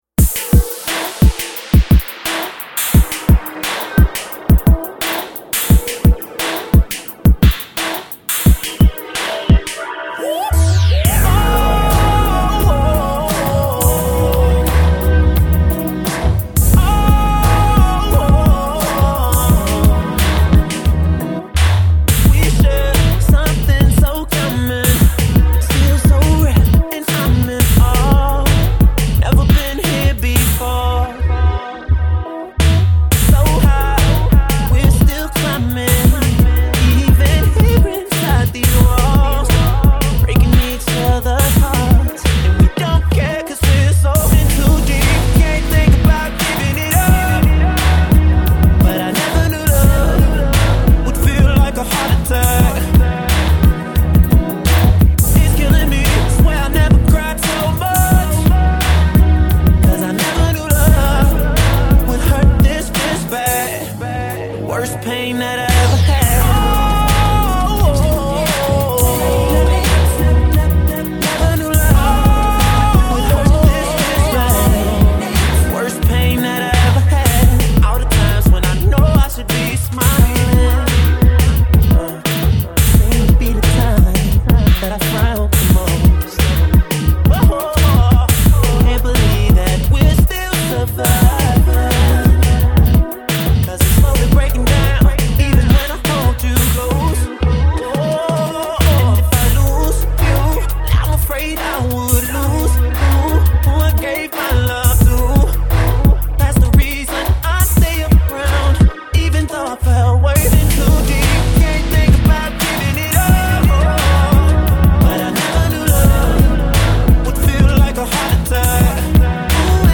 Remixes